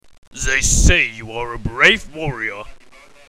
Englische Sprecher (m)